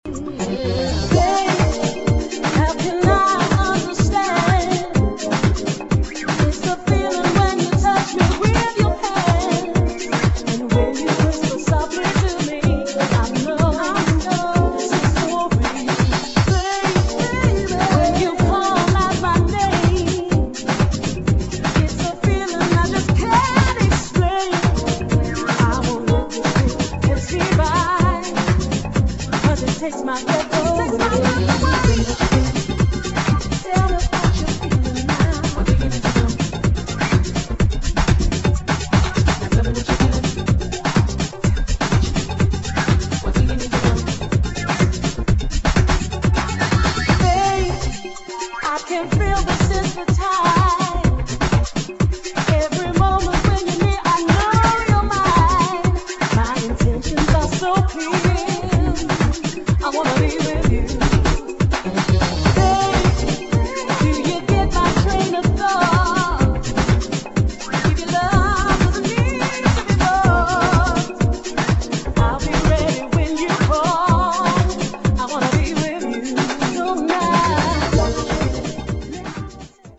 [ JAZZ / FUNK / SOUL / HOUSE ]